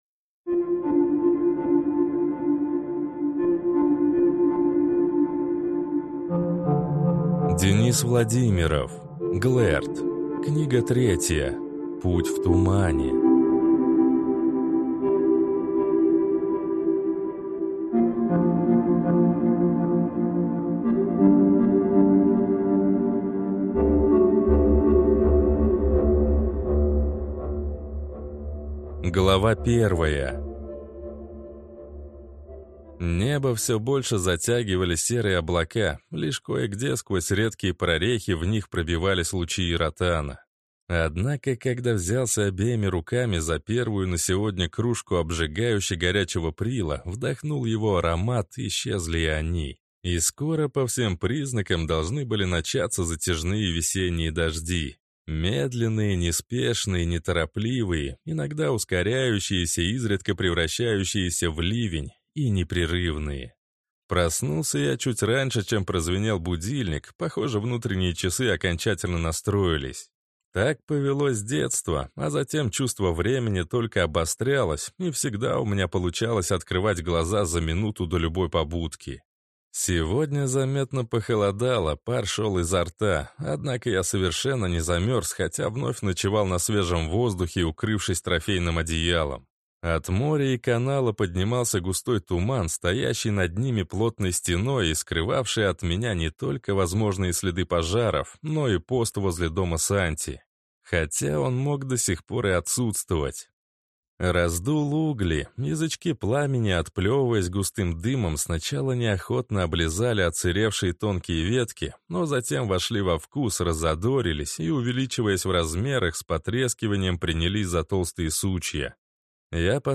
Аудиокнига Глэрд. Книга 3. Путь в тумане | Библиотека аудиокниг